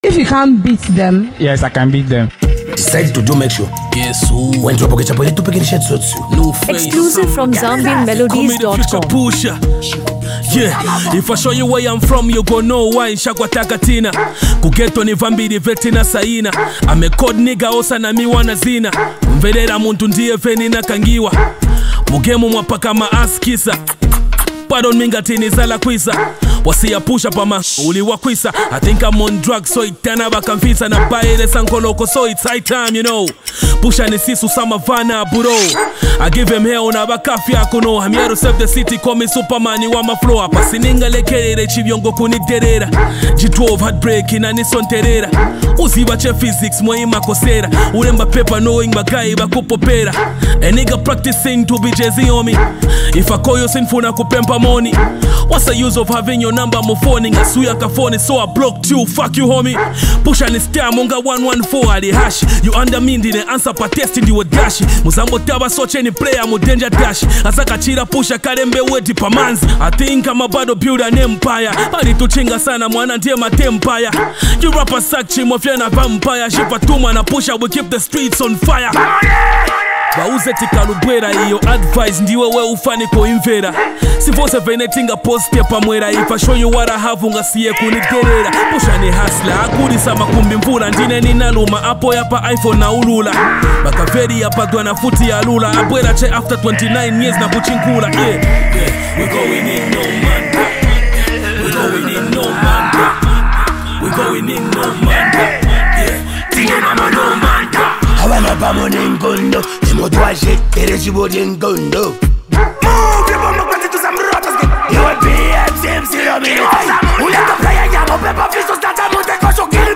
delivers sharp verses with a commanding presence
adds his unique vocal texture